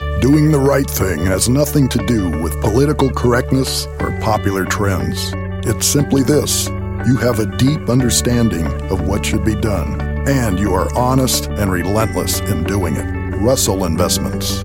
Male
a deep baritone voice with some grit and a tone of wisdom, authority, warmth and trust
Corporate/Business/Investment